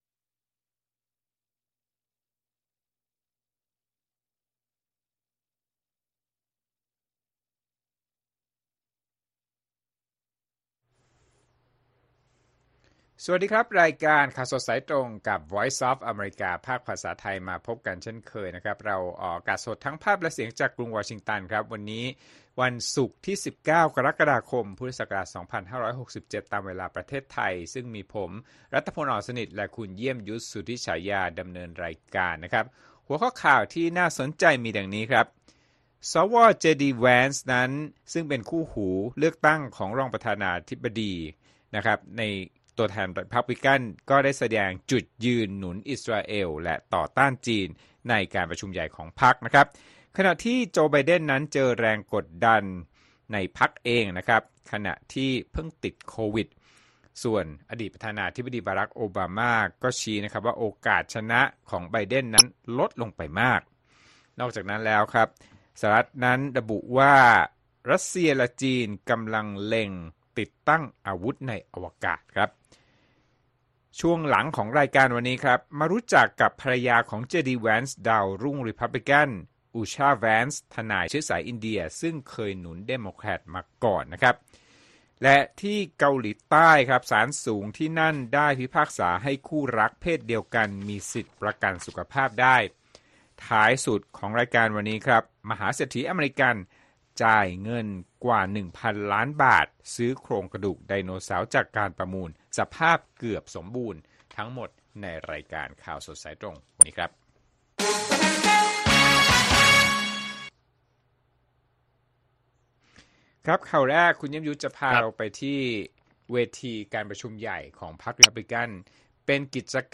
ข่าวสดสายตรงจากวีโอเอไทย วันศุกร์ ที่ 19 ก.ค. 2567